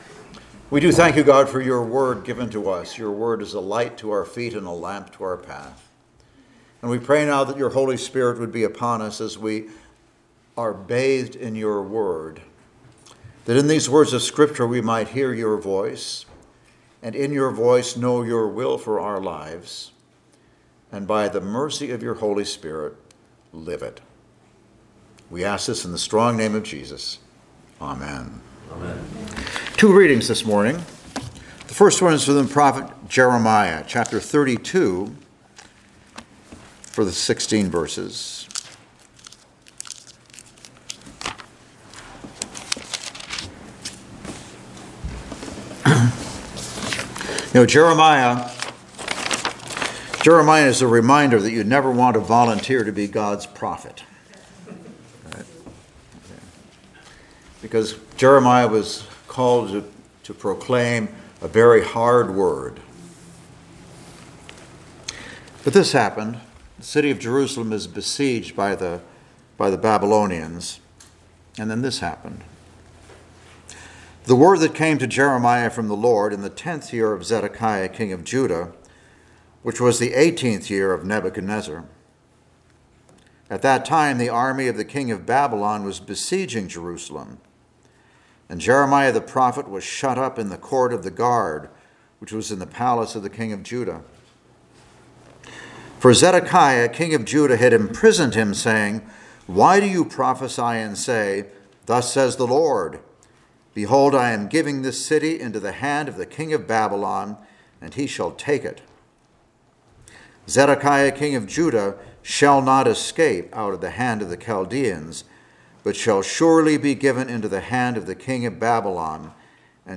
Service Type: Sunday Morning Worship Topics: The Great Reversal , Trust God